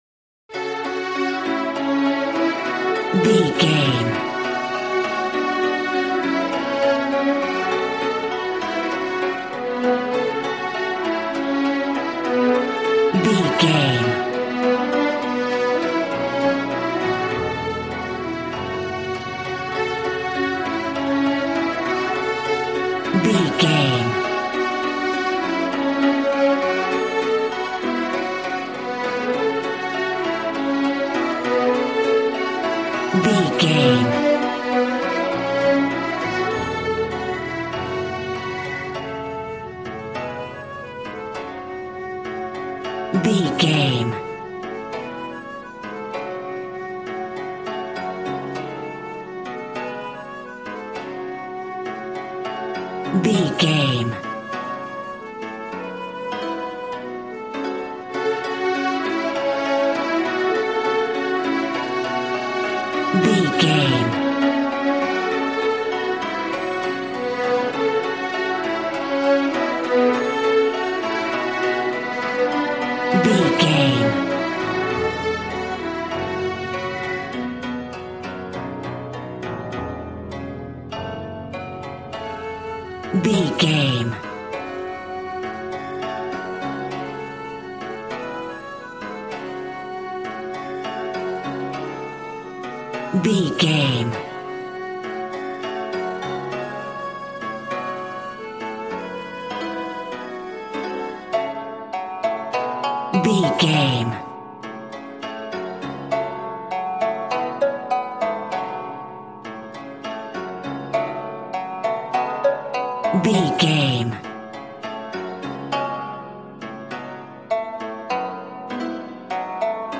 Aeolian/Minor
G♭
happy
bouncy
conga